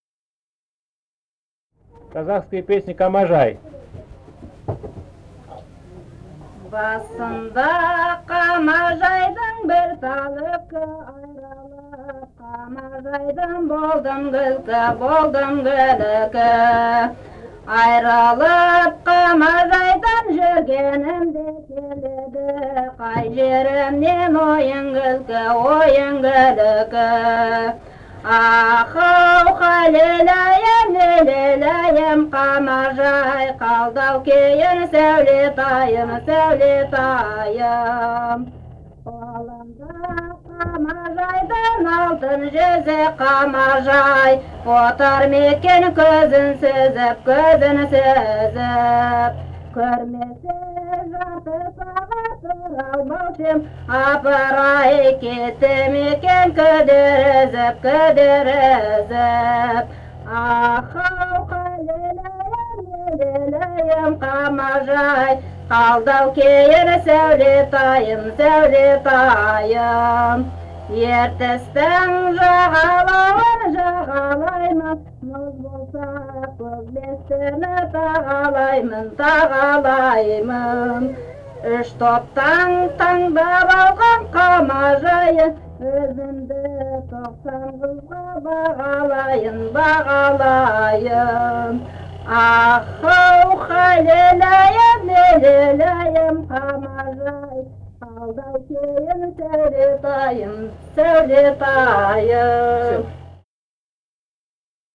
Этномузыкологические исследования и полевые материалы
Алтайский край, д. Алексеевка Чарышского района, 1967 г. И1020-04